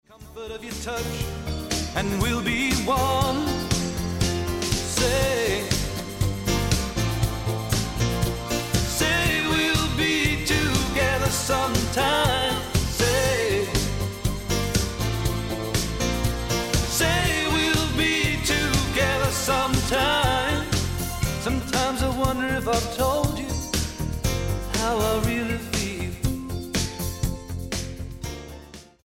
STYLE: Pop
with elements of soul